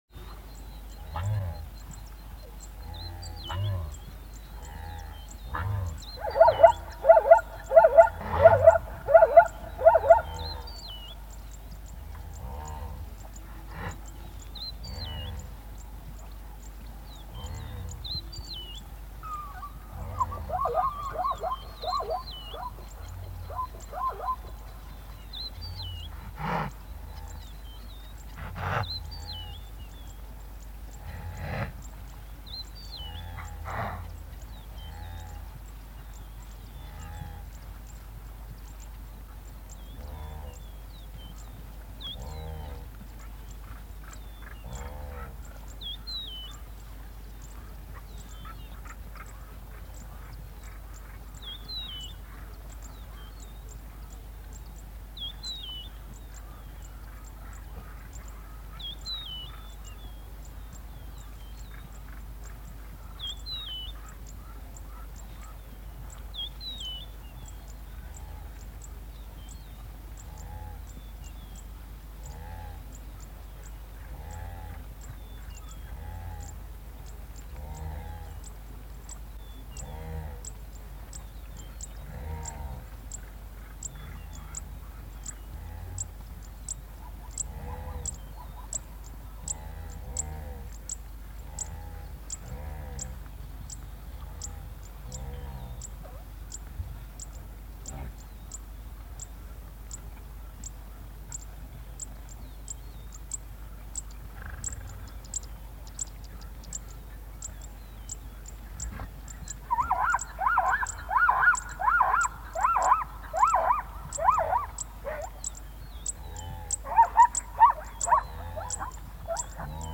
دانلود صدای گورخر 6 از ساعد نیوز با لینک مستقیم و کیفیت بالا
جلوه های صوتی